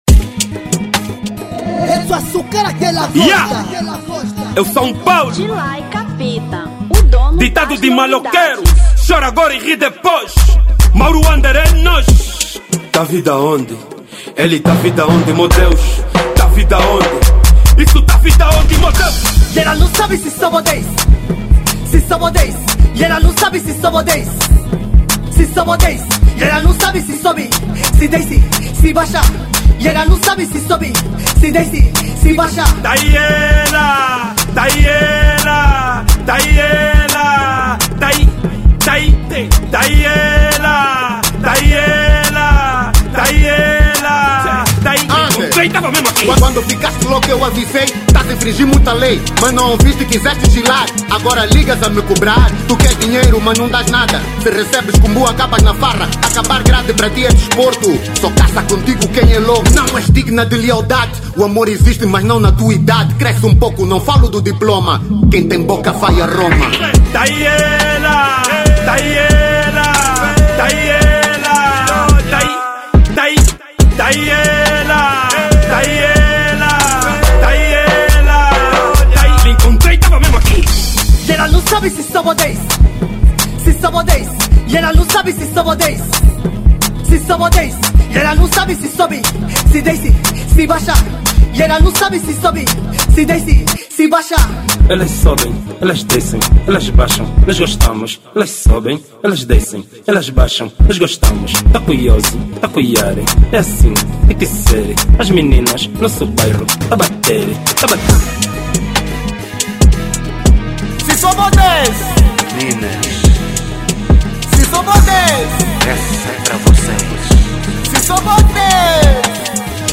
Afro Trap 2025